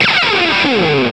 GTR MED S0AL.wav